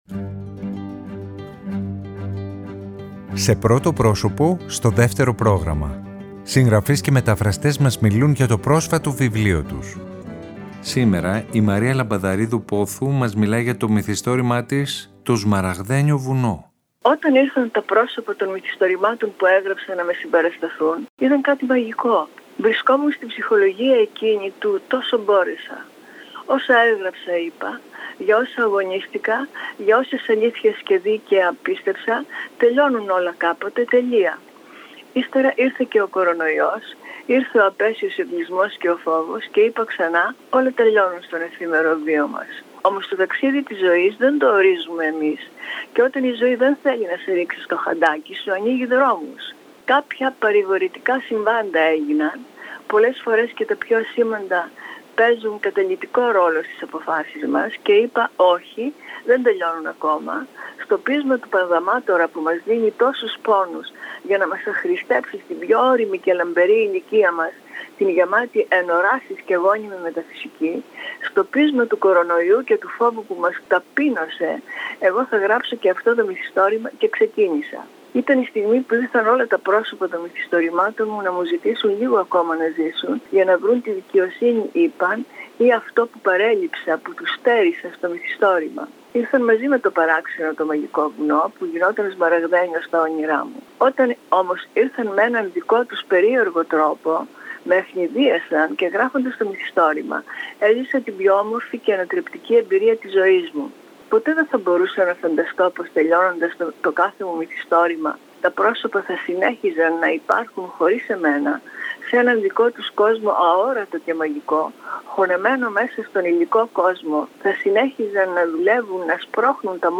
Σήμερα η Μαρία Λαμπαδαρίδου Πόθου μας μιλάει για το μυθιστόρημα της «Το σμαραγδένιο βουνό».